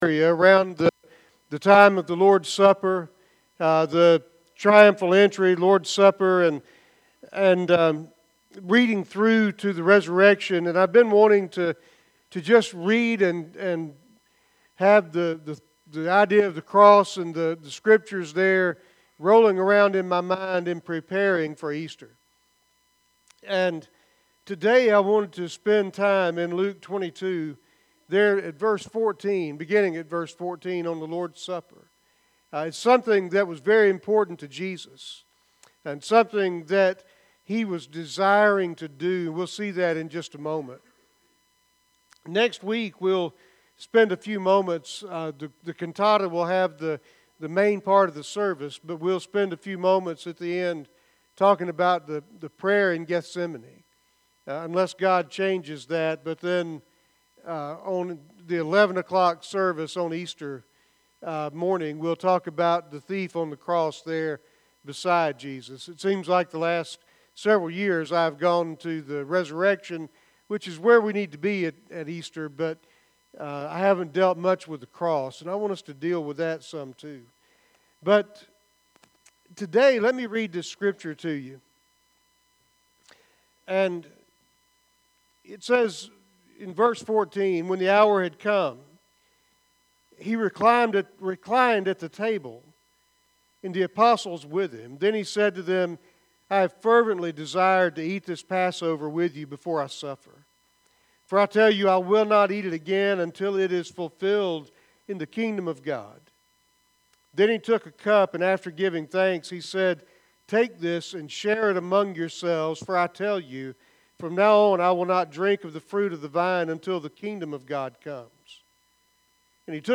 Sermons | Center Hill Baptist Church of Gratis